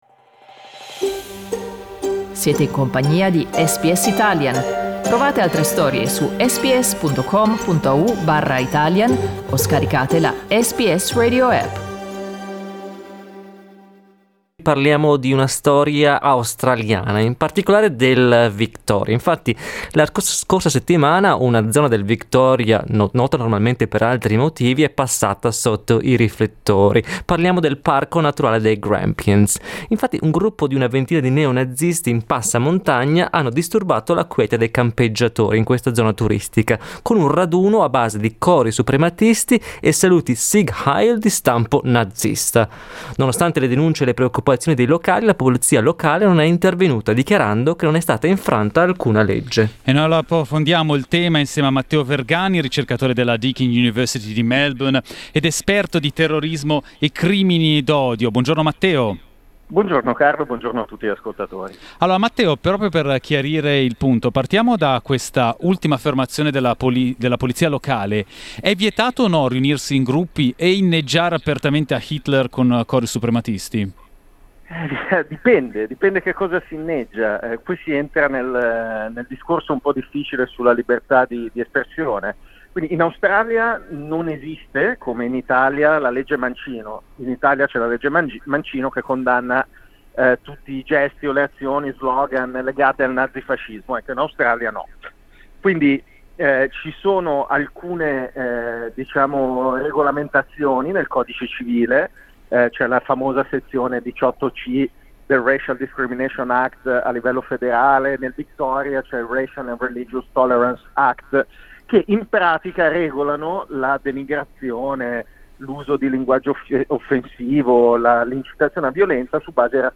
Ascolta l'intervista integrale: LISTEN TO È vietato inneggiare a Hitler in Australia?